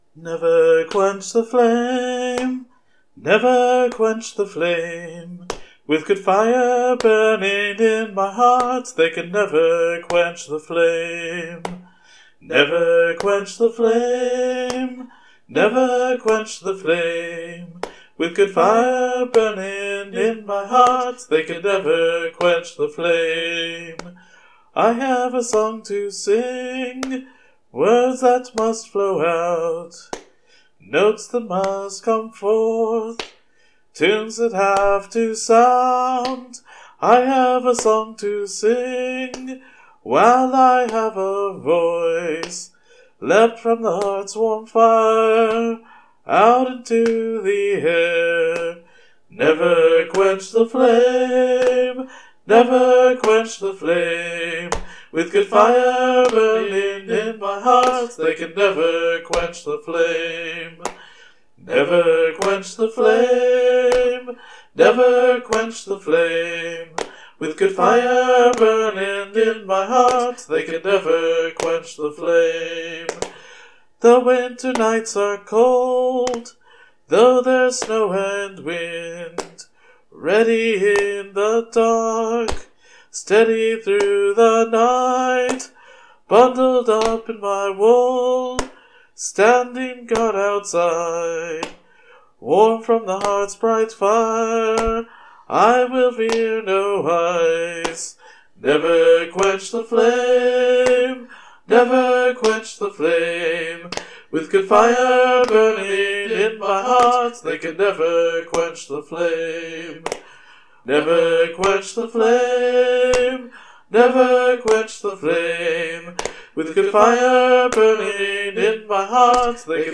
Protest Song: Never Quench the Flame
2. It should be simple enough and repetitive enough that everyone can manage the chorus at least after hearing it a bit.